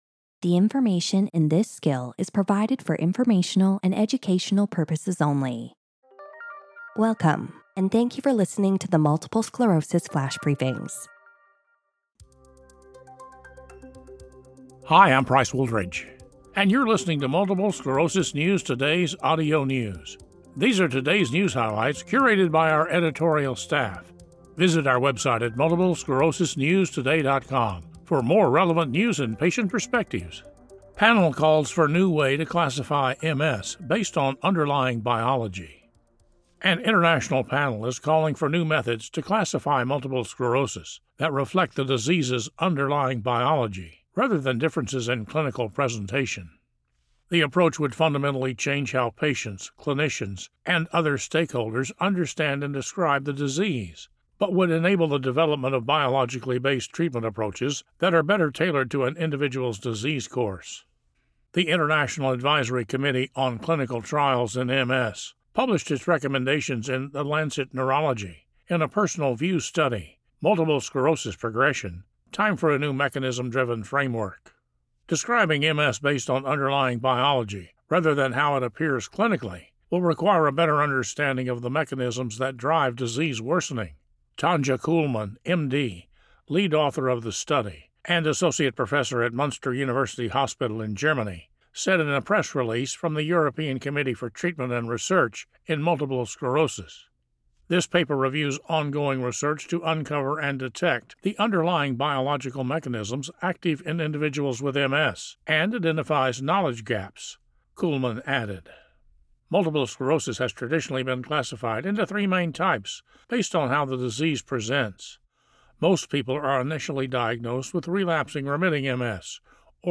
reads about the calls for new methods to classify MS that reflect the disease's underlying biology.